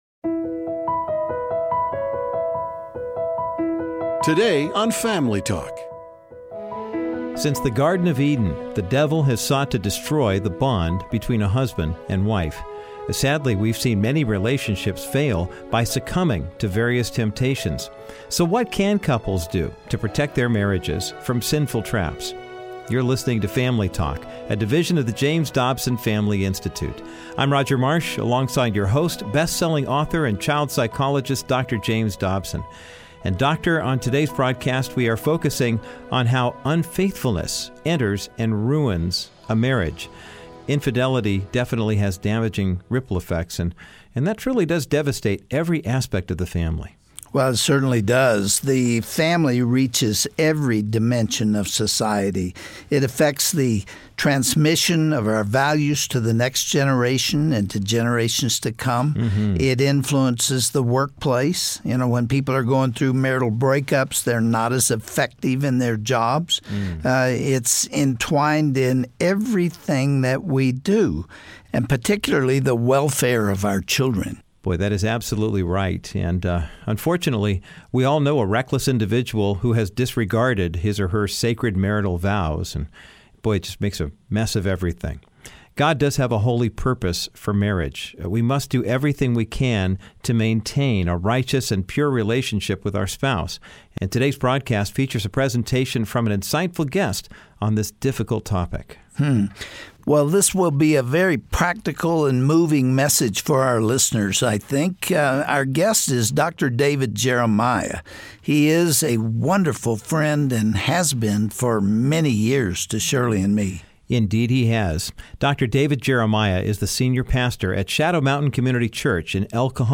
On this Family Talk broadcast, well-known and respected pastor, Dr. David Jeremiah, talks about the prevalent and dangerous sin of infidelity. He identifies the origin of this immorality, and the gradual ways adultery can enter a relationship.